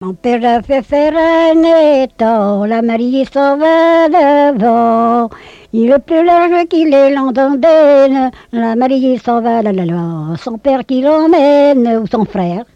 Genre laisse
collecte en Vendée